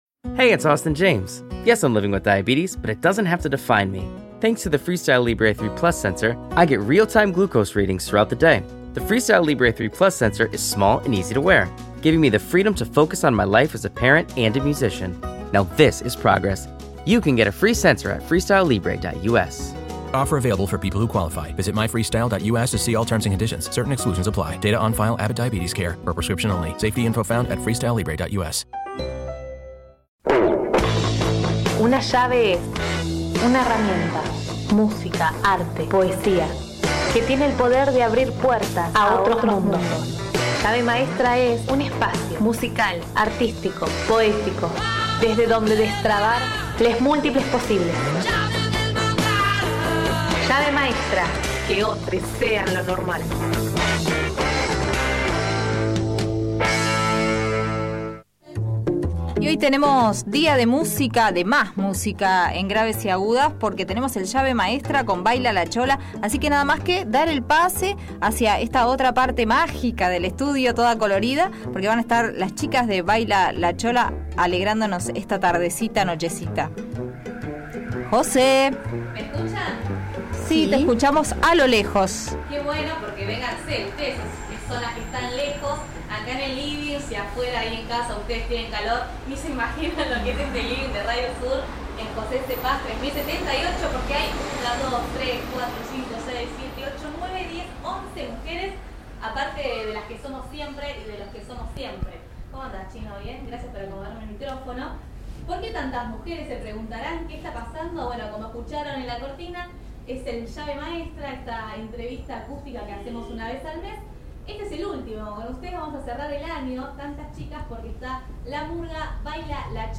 Una murga de estilo uruguayo, integrada por 20 murguistas, mujeres que se reconocen en otras mujeres, mujeres que se apropian del género para cantar sus batallas. Cantan contra la violencia contra las mujeres, sobre la legalización del aborto, la trata de personas y también sobre la fuerza de las mujeres cuando se unen.
Vinieron al living de nuestra Radio Sur y cerraron el ciclo Llave maestra.